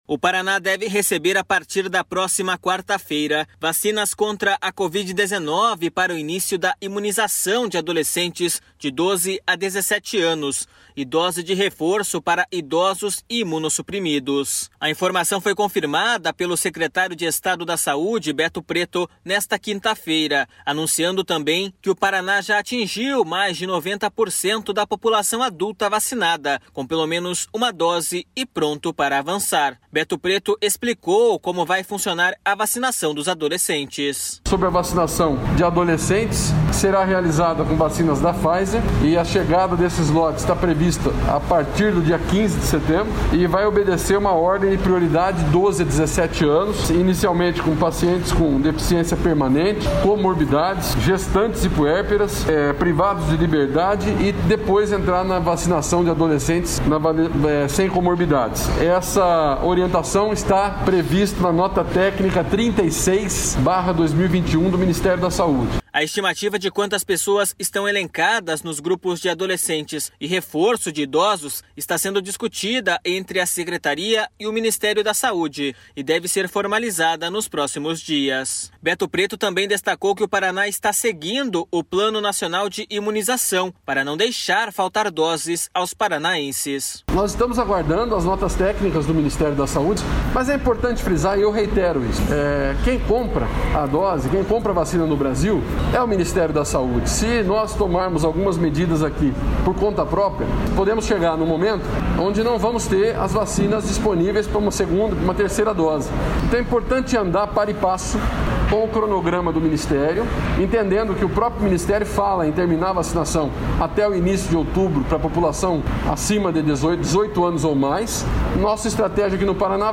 Beto Preto explicou como vai funcionar a vacinação dos adolescentes.// SONORA BETO PRETO.//